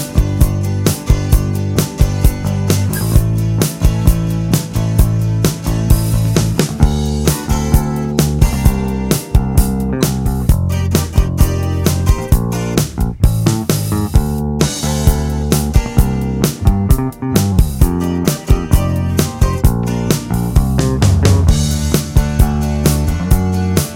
no Piano Pop (1980s) 4:37 Buy £1.50